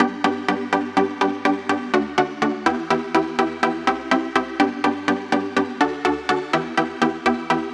まずは単純にフィルターをかけてみましょう。
このセクションは単純にローパスフィルターのようなのでリードサウンドがちょっとこもったプラック感あるサウンドに変化しました。
Nexus-例-フィルタープラック.mp3